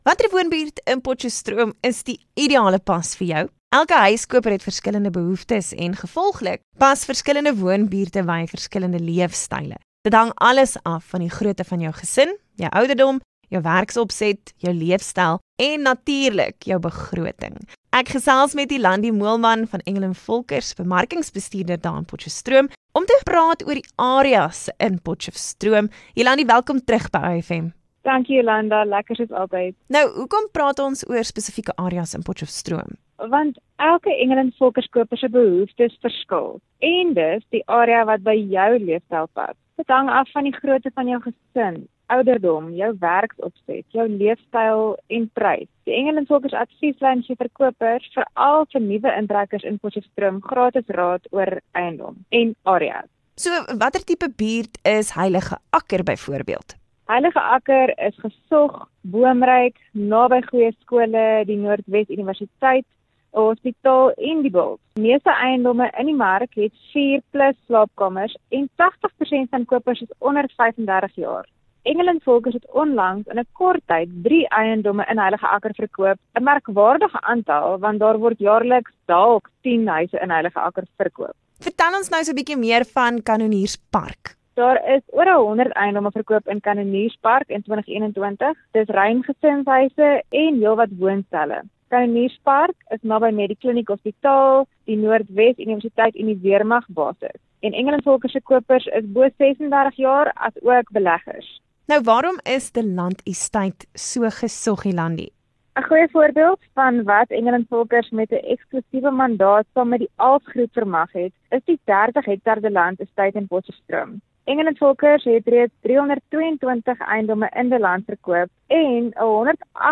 11 May Engel & Volkers 13 May 2022 interview